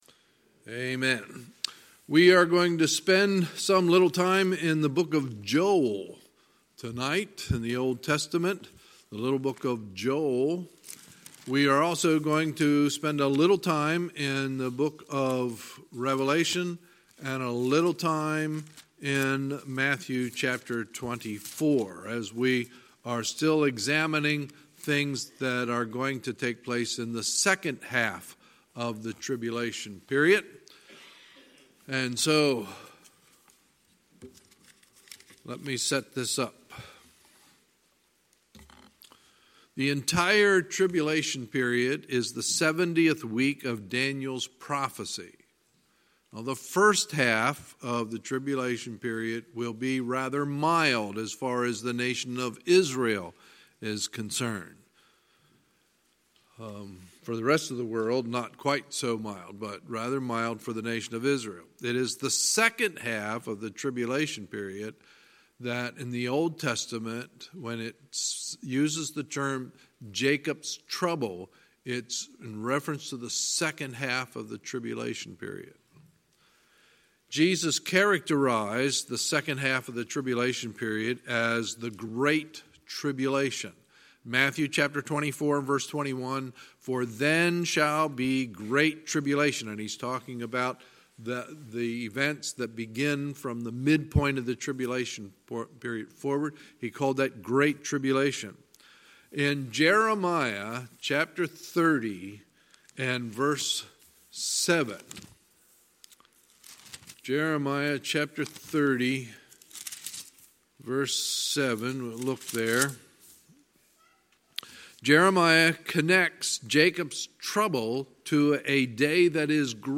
Sunday, April 28, 2019 – Sunday Evening Service